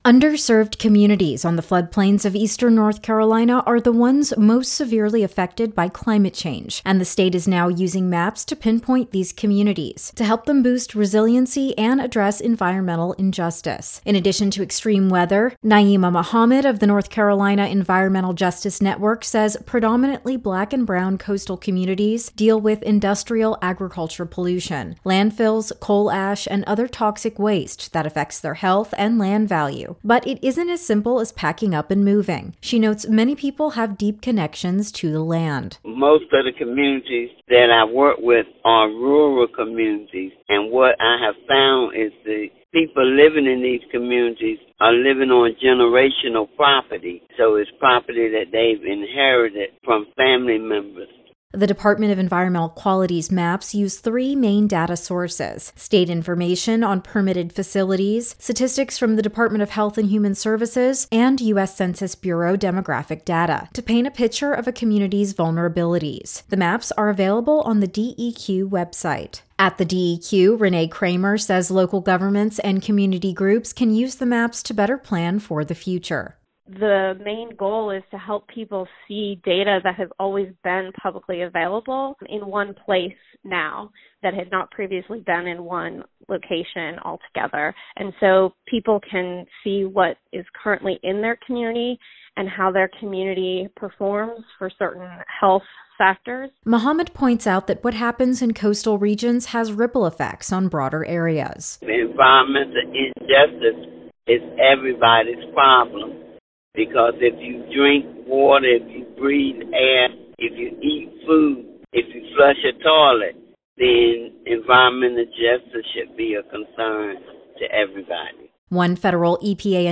THE FOLLOWING RADIO REPORTS ARE DONE IN PARTNERSHIP WITH PUBLIC NEWS SERVICE.